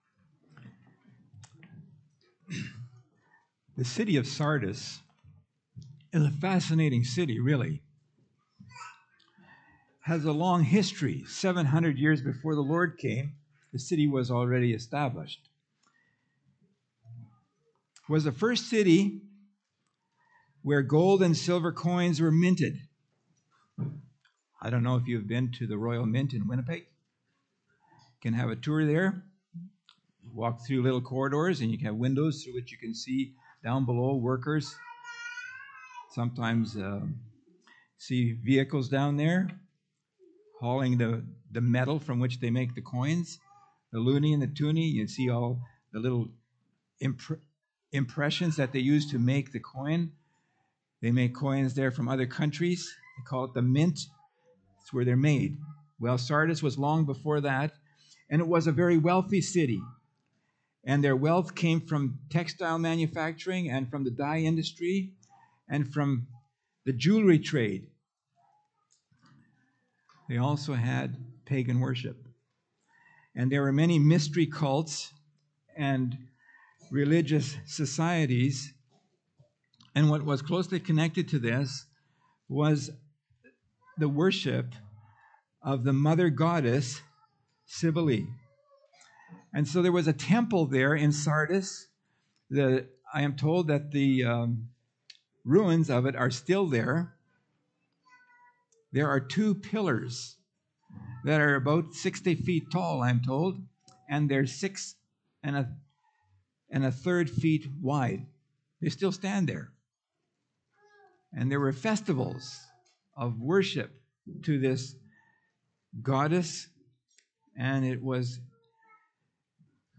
Revelation Category: Pulpit Sermons Key Passage: Revelation 3:1-6 https